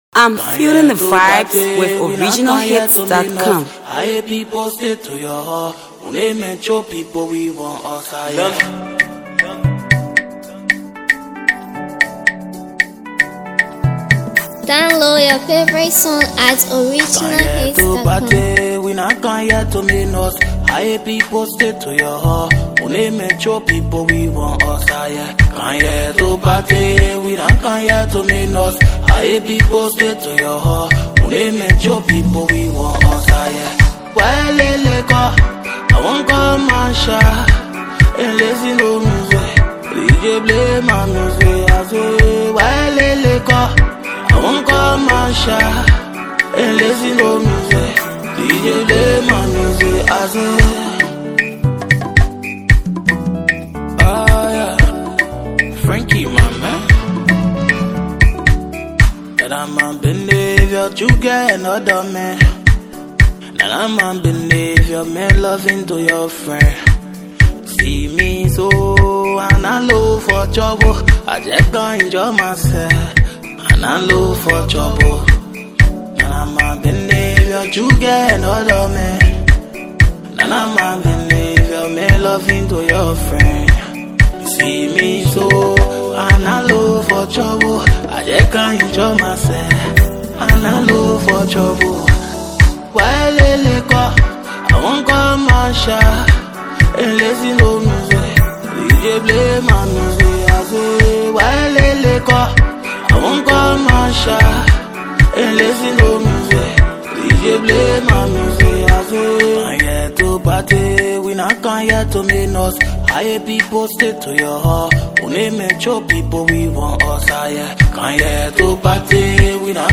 is a catchy, danceable banger